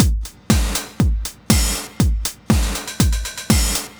Index of /musicradar/retro-house-samples/Drum Loops
Beat 01 Full (120BPM).wav